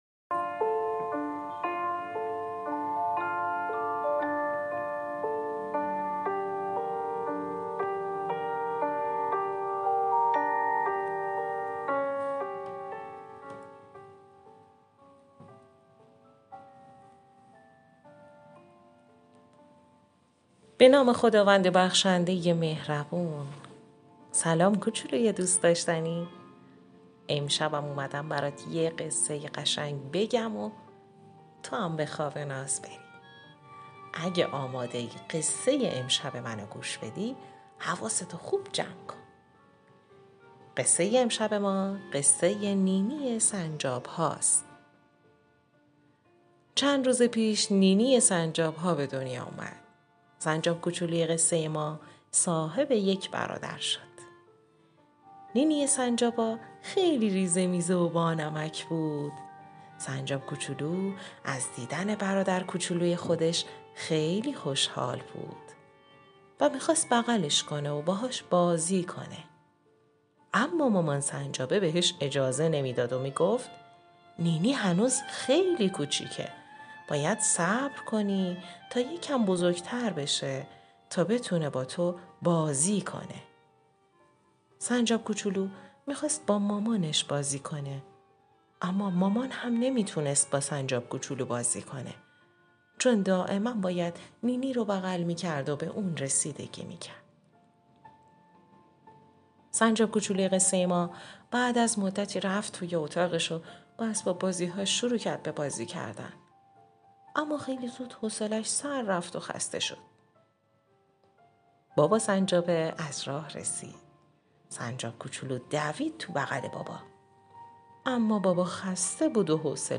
دانلود قصه صوتی کوتاه کودکانه نی نی سنجاب ها